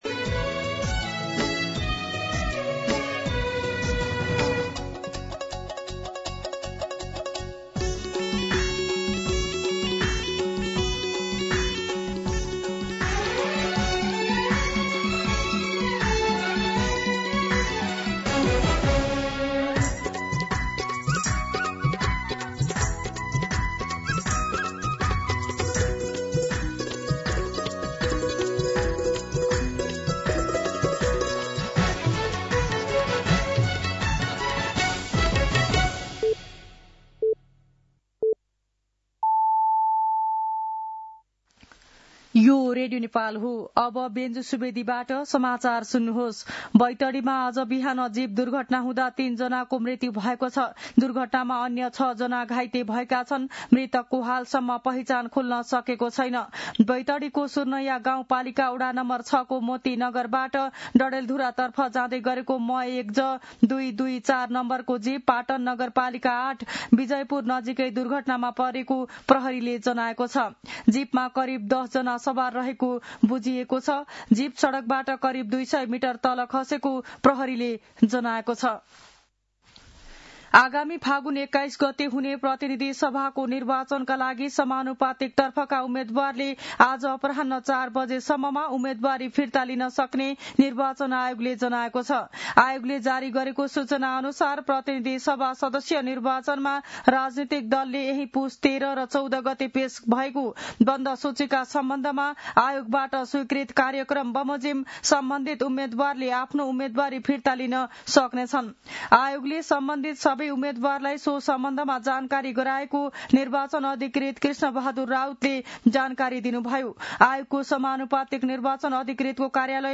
मध्यान्ह १२ बजेको नेपाली समाचार : २८ पुष , २०८२